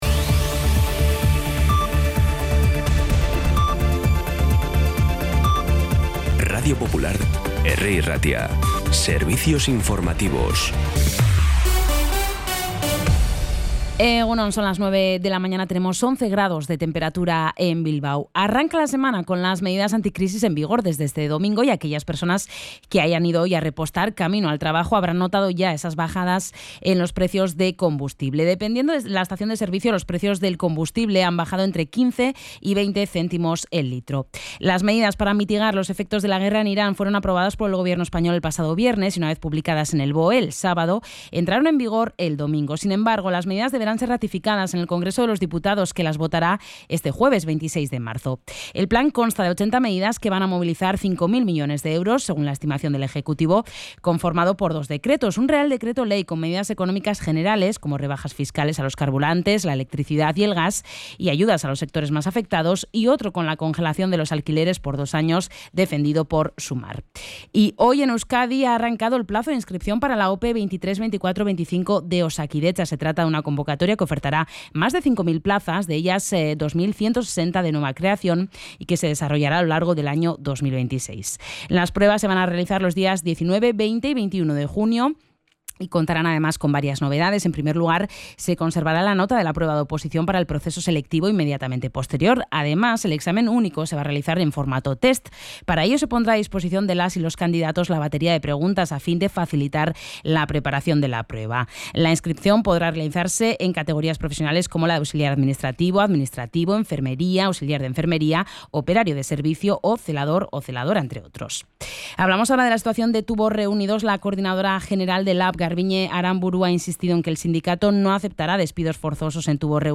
Las noticias de Bilbao y Bizkaia de las 9 , hoy 23 de marzo
Los titulares actualizados con las voces del día. Bilbao, Bizkaia, comarcas, política, sociedad, cultura, sucesos, información de servicio público.